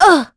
Valance-Vox_Damage_03.wav